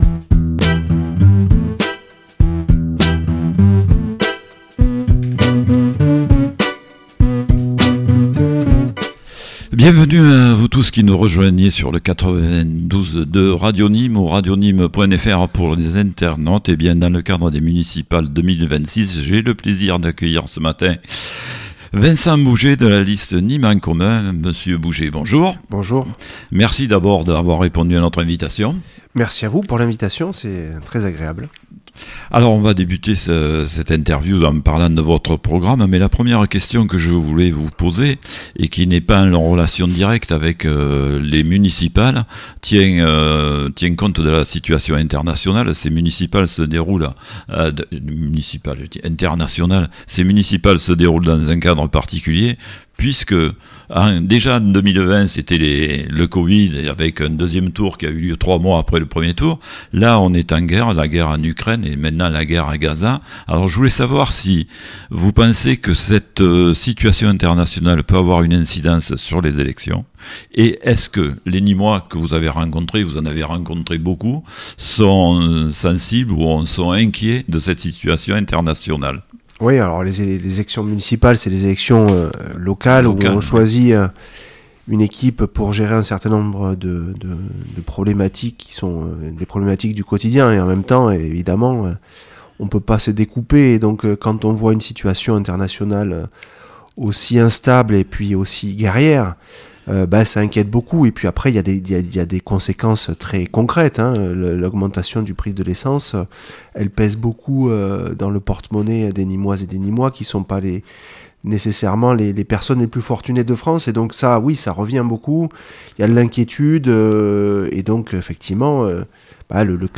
Entretien
Émission spéciale élections municipales 2026